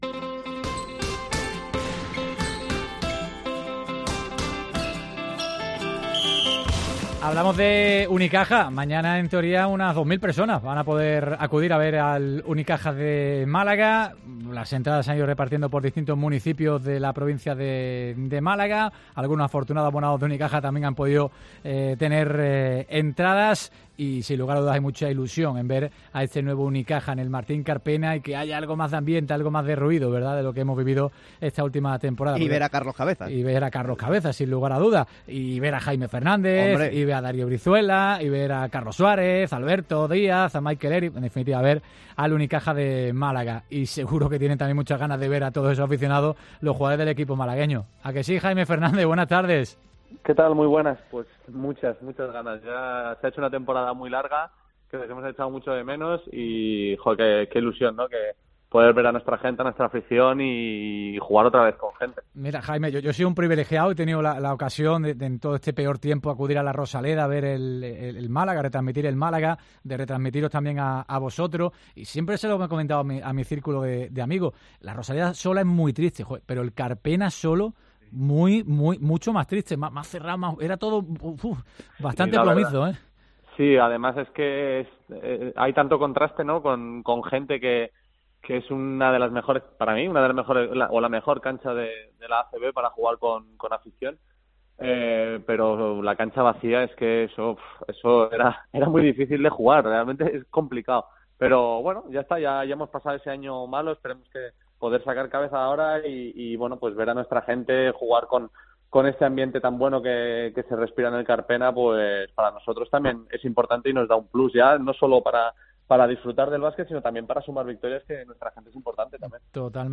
AUDIO: El jugador de Unicaja analizó en COPE como está transcurriendo la pretemporada